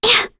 Selene-Vox_Attack2.wav